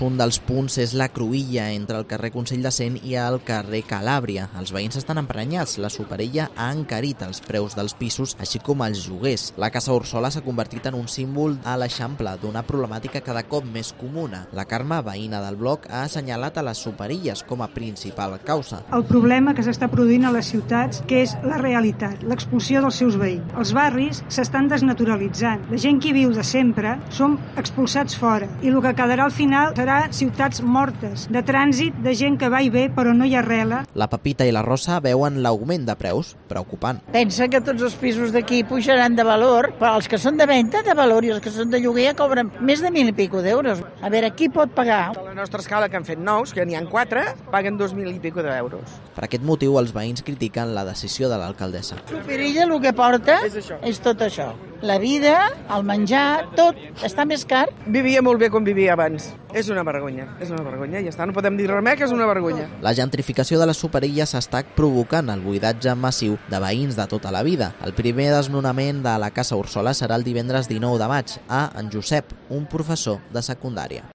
crónica frente a la Casa Orsola sobre el encarecimiento de los precios en la superilla